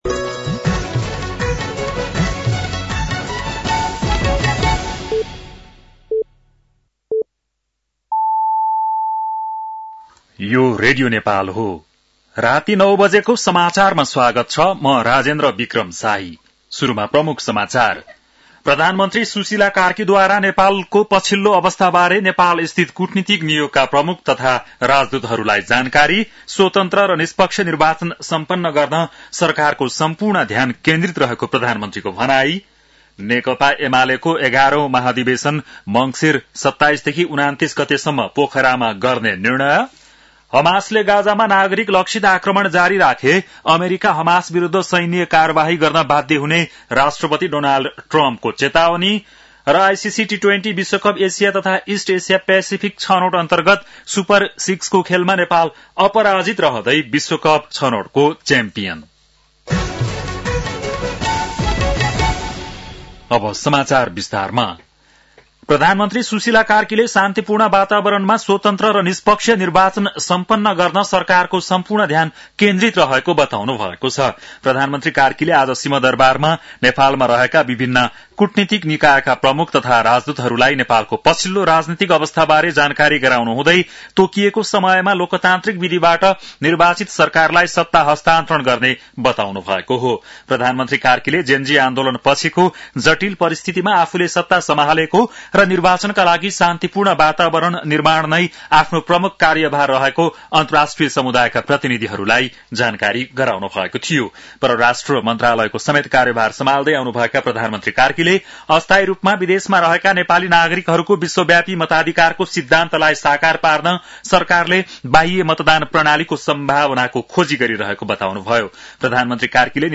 बेलुकी ९ बजेको नेपाली समाचार : ३१ असोज , २०८२